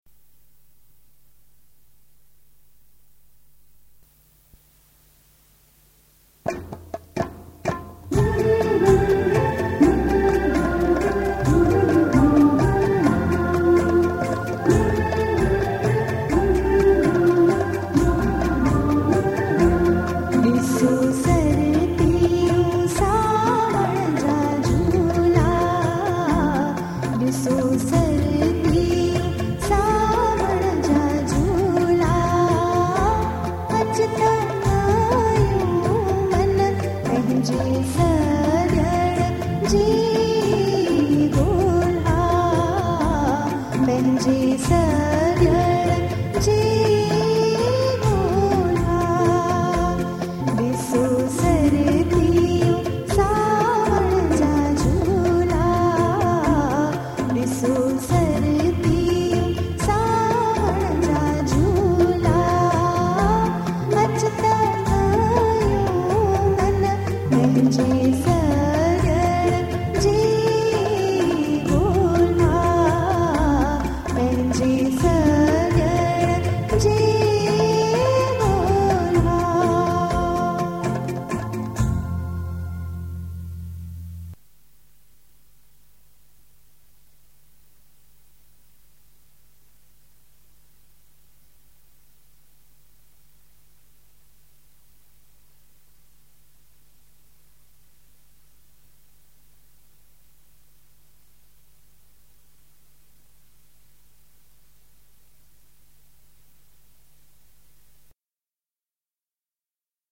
Sindhi Songs